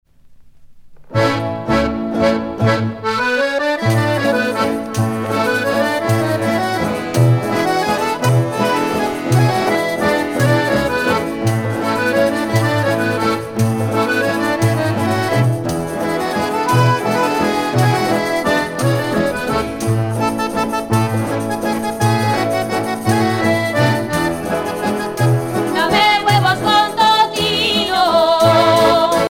danse : jota (Espagne)
Pièce musicale éditée